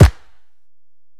SouthSide Kick Edited (3).wav